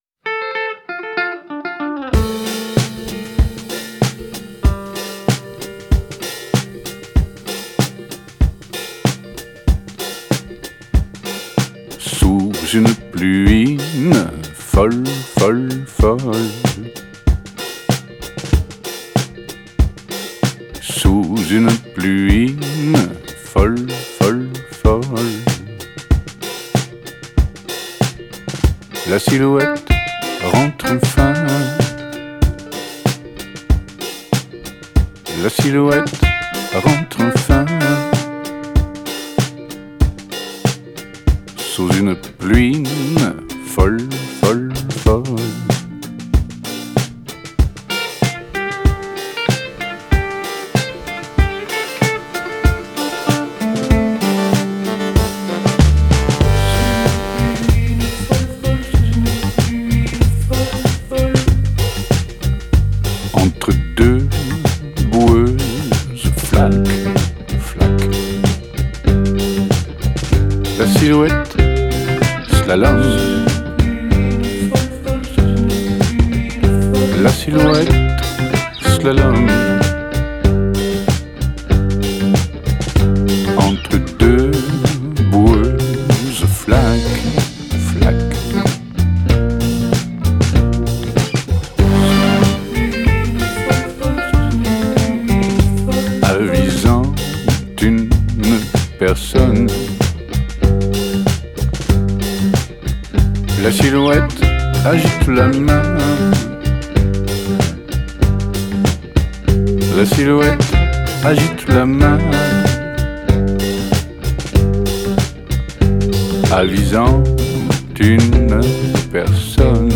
Genre: World, French Pop, Indie, Chanson